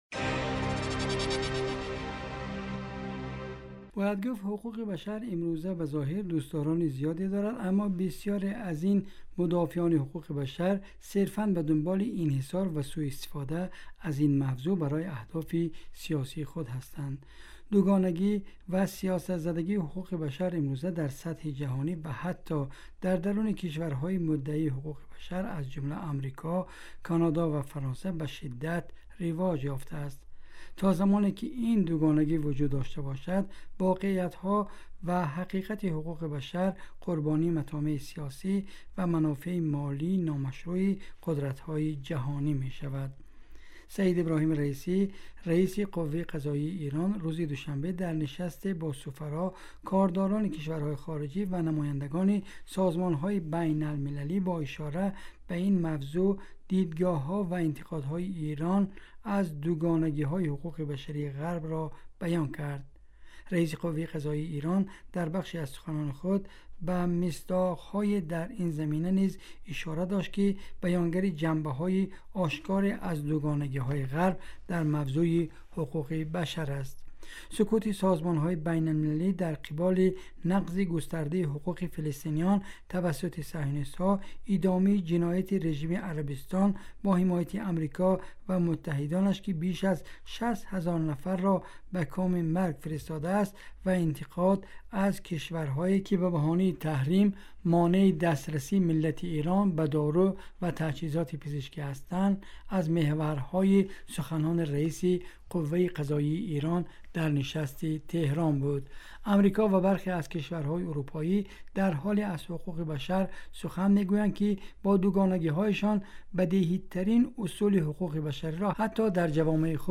Эрон дар зумраи фаъолтарин кишварҳо дар заминаи ҳуқуқи башар (гузориши вижа)